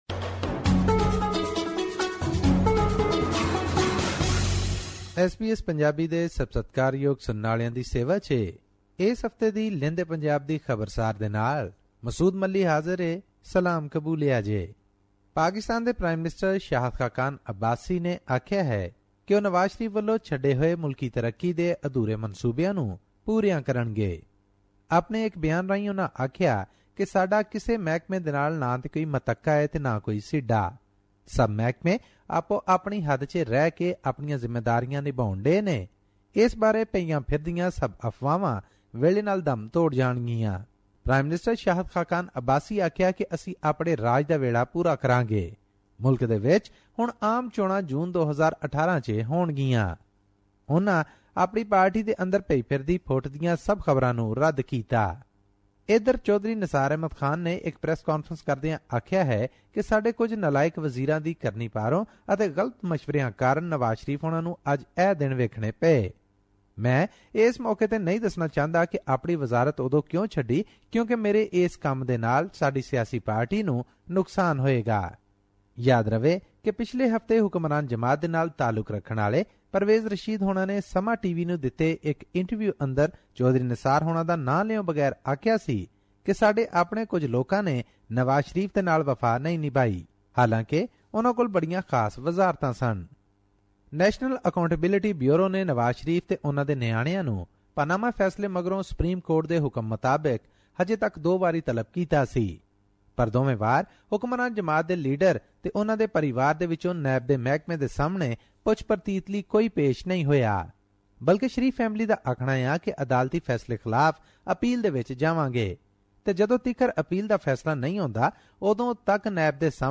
In this weekly report from West Punjab, hear about the World XI cricket team, the Sri Lanka cricket team and others heading to Pakistan for tournaments in the next few months.